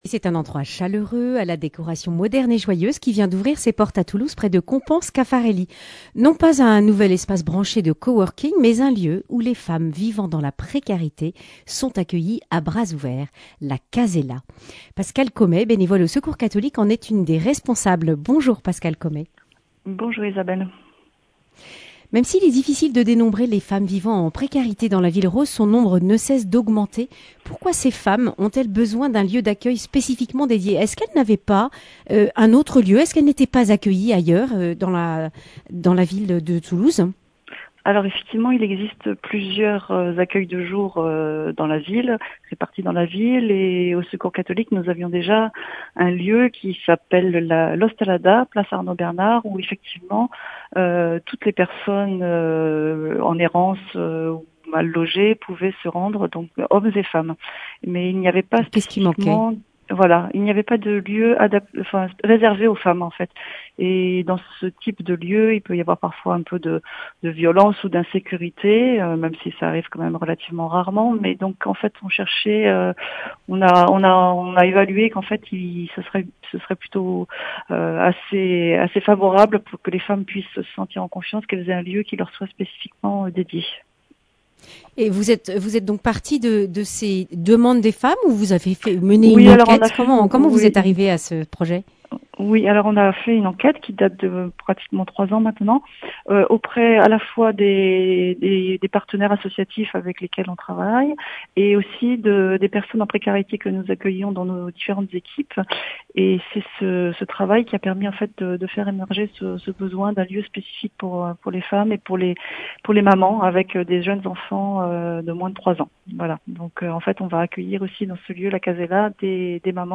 mardi 8 mars 2022 Le grand entretien Durée 11 min